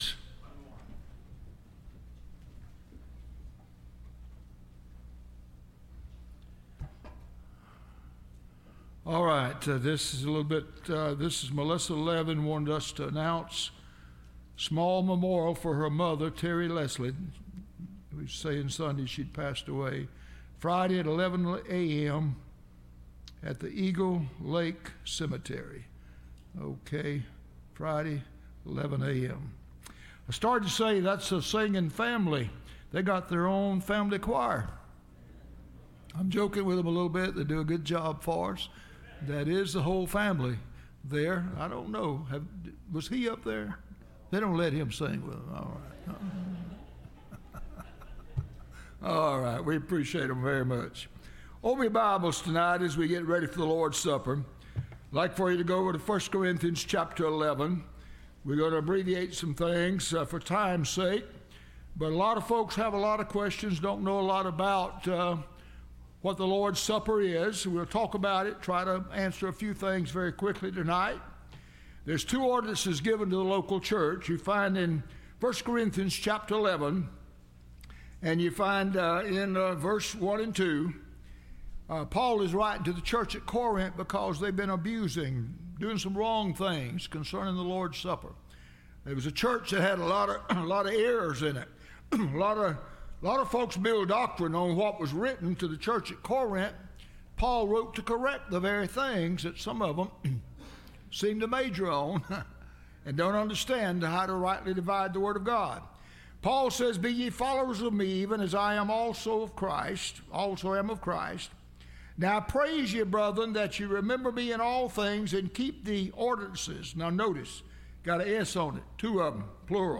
Lords Supper Service – Landmark Baptist Church